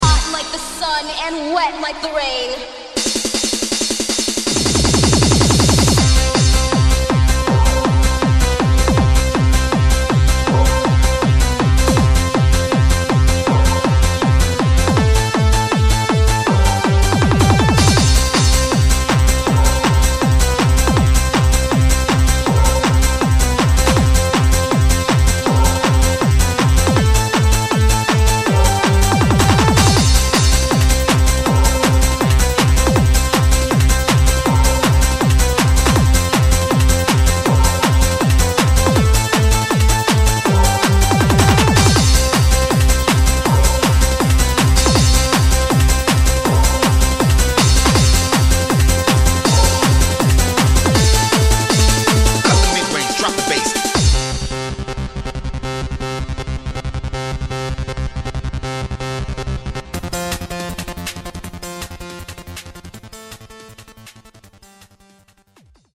Hard-Trance, Progressive House